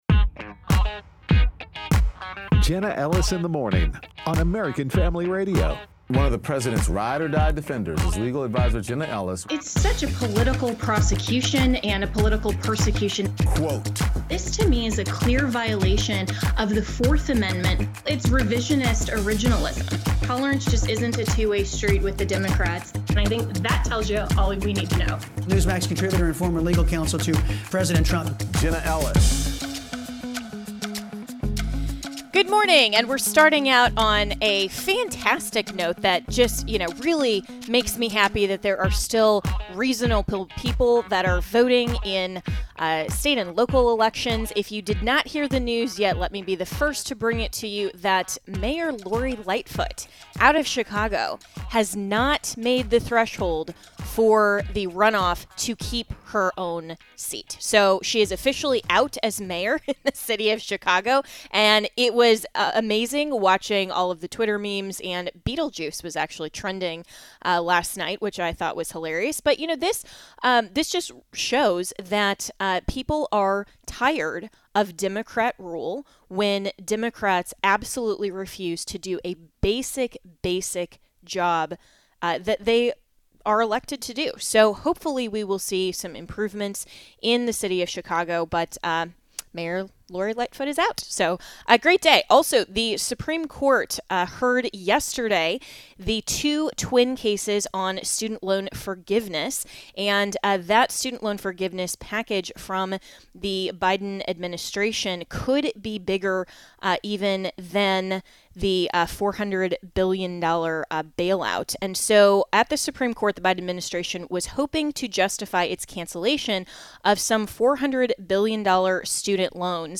Interviews With Cong. Mike Collins and Presidential Candidate Vivek Ramaswamy